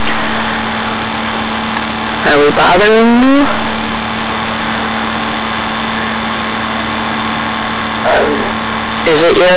These recordings were taken using a General Electric Microcassette Recorder, Model #3-5326A using a Radio Shack MC-60 Microcassette at normal speed.
"Are we bothering you?" - Answer was filtered (low pass) using audacity.